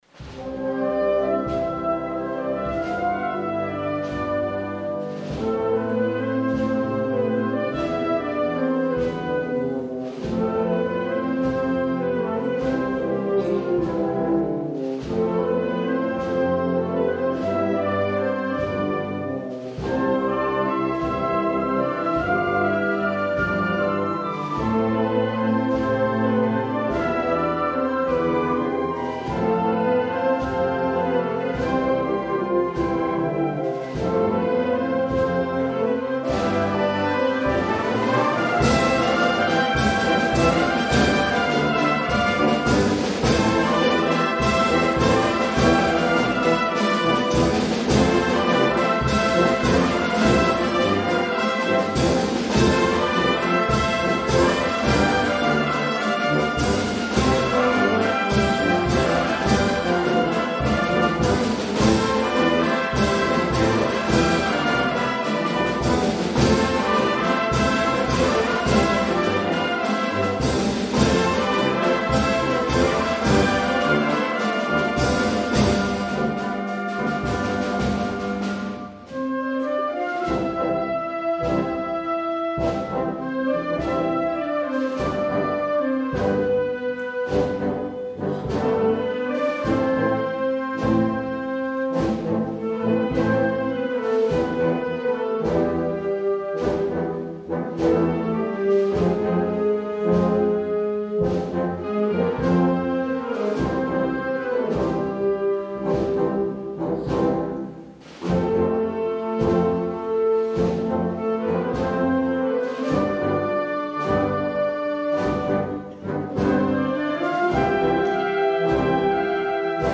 Concert de Setmana Santa - Auditori de Porreres.